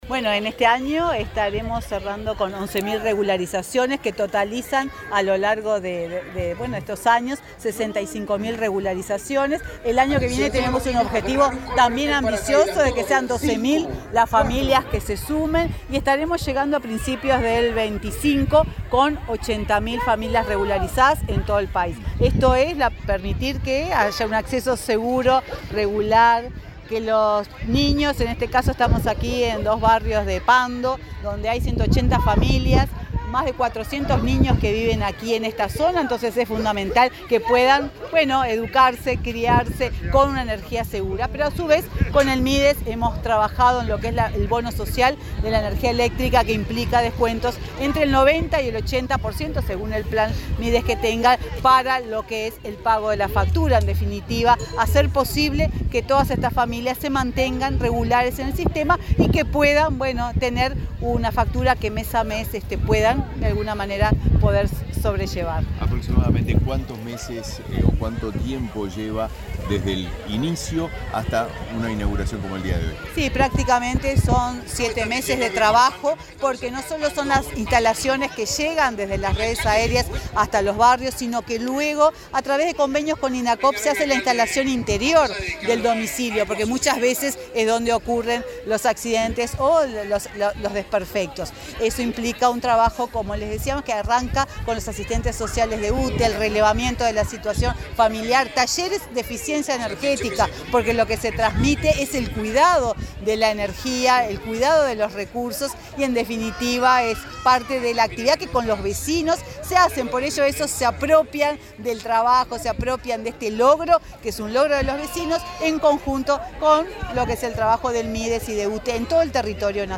Declaraciones de la presidenta de UTE y del ministro de Desarrollo Social
Declaraciones de la presidenta de UTE y del ministro de Desarrollo Social 20/12/2023 Compartir Facebook X Copiar enlace WhatsApp LinkedIn Este martes 19, la presidenta de UTE,, Silvia Emaldi, y el ministro de Desarrollo Social, Martín Lema, inauguraron las obras de regularización del barrio Villa “El Bosque” y viviendas "El Porvenir", de la ciudad de Pando, en el departamento de Canelones.